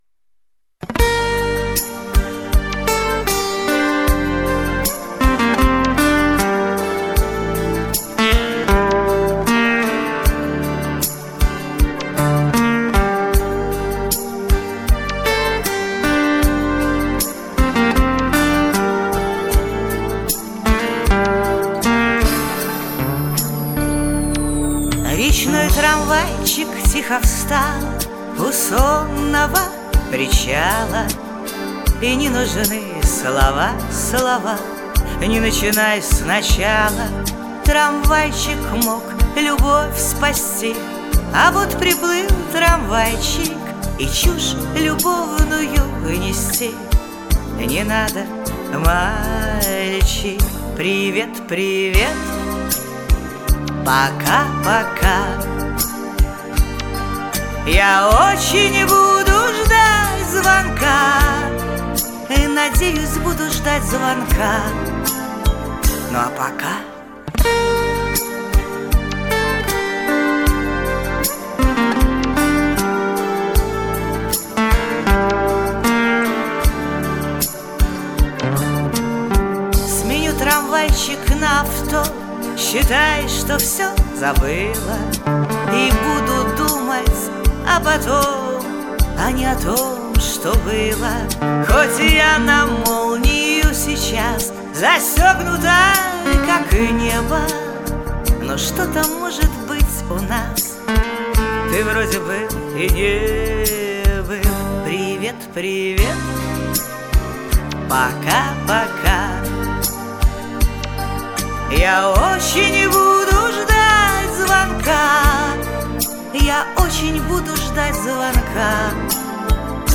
Профессионально поете.